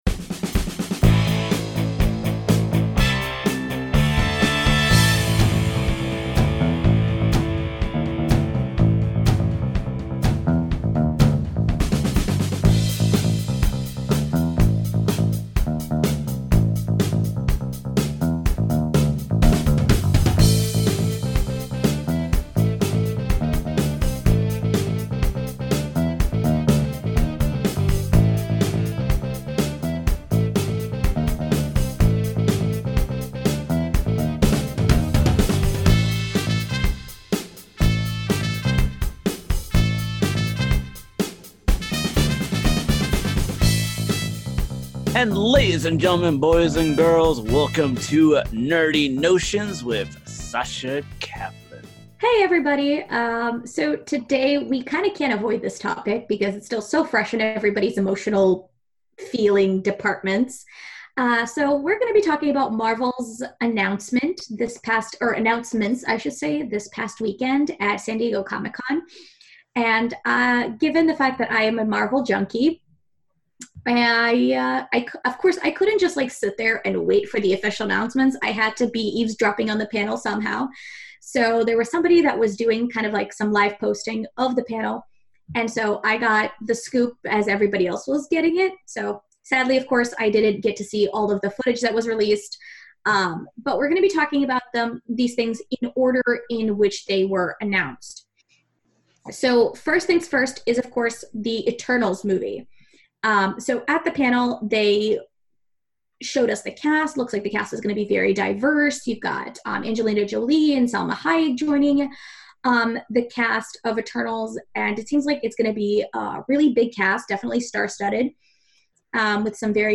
Opening Music from https